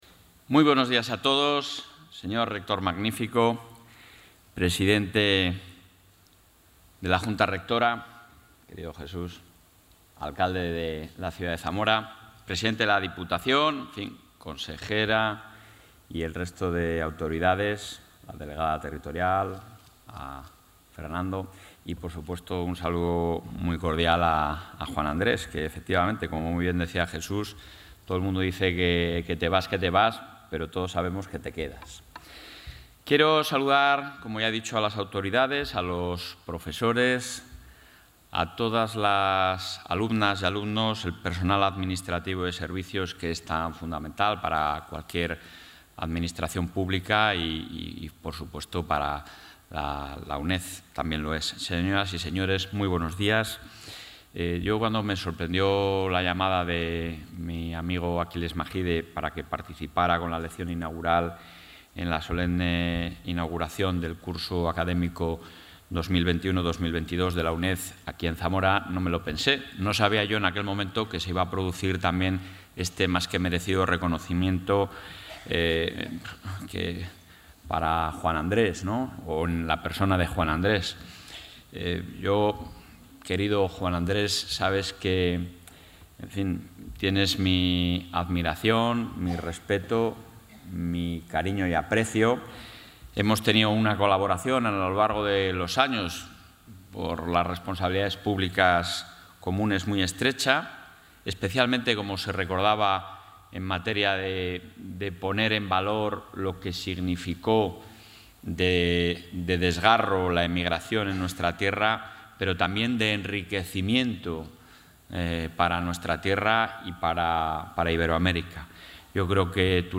Audio presidente.
En la inauguración del curso 2021-2022 de la UNED en Zamora, Fernández Mañueco ha defendido que en el imaginario colectivo de la Comunidad está muy arraigada la idea de la educación como mejor garantía de futuro.